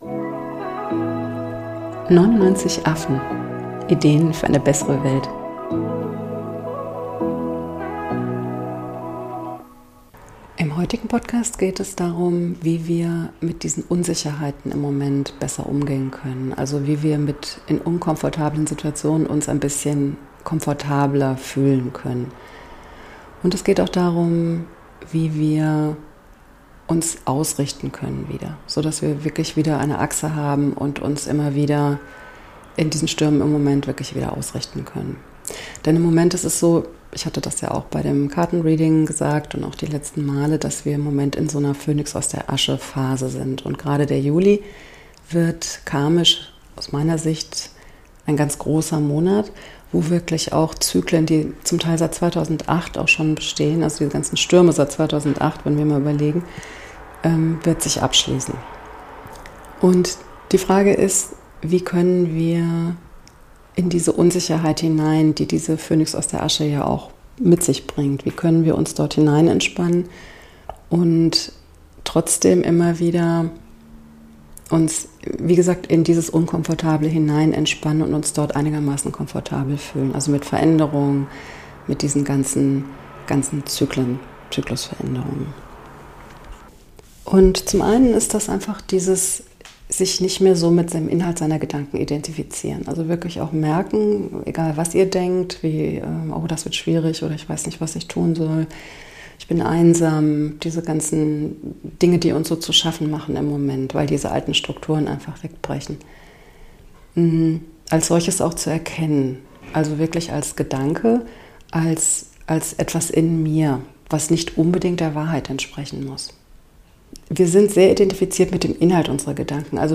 Und wie wir uns wieder verbinden und ausrichten können, so daß wir alles nicht alleine stemmen müssen und Rat, Trost und Hilfe von oben bekommen. Sorry für die Nebengeräusche - wir ziehen gerade um :)